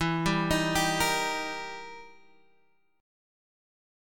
E Minor Major 7th Flat 5th